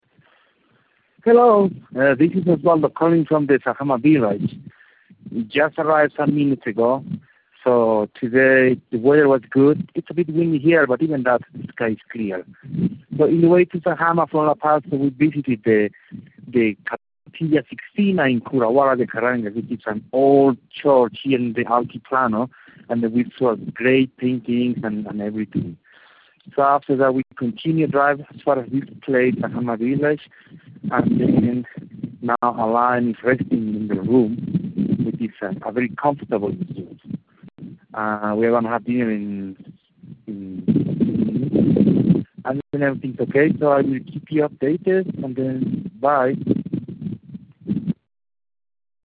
Bolivia Everest Prep Climb Dispatch